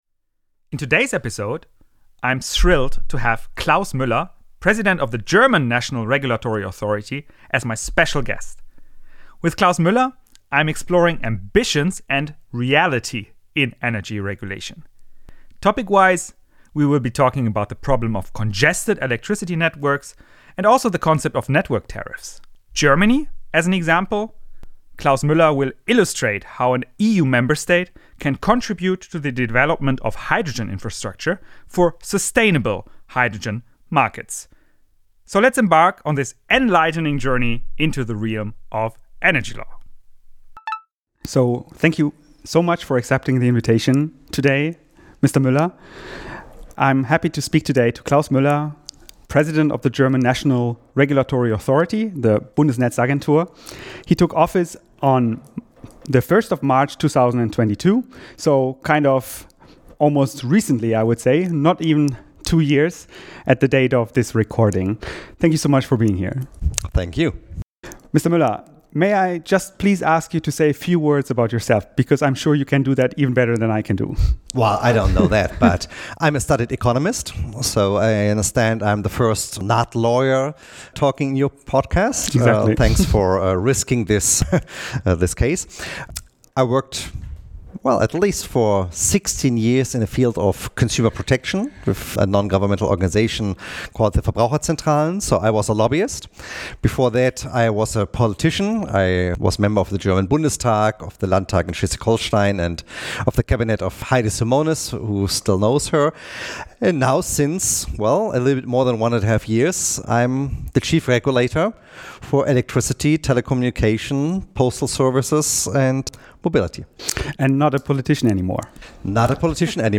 The interview took place on 21 December 2023.